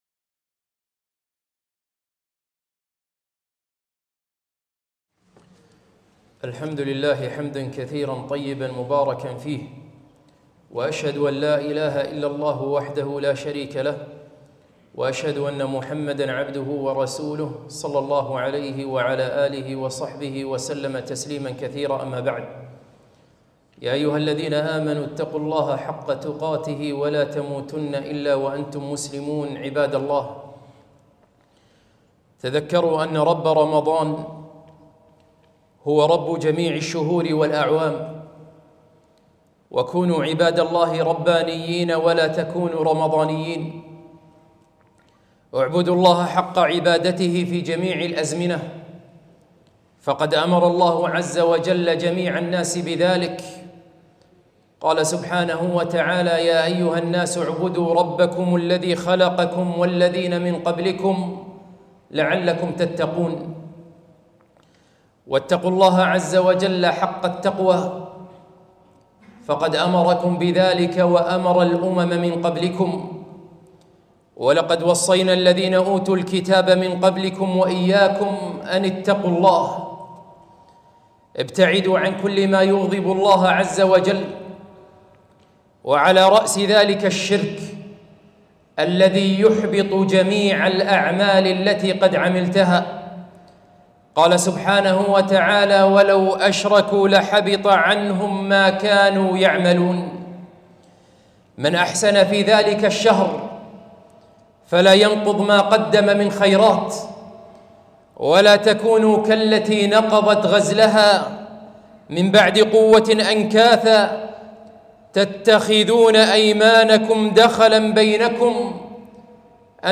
خطبة - ماذا بعد رمضان؟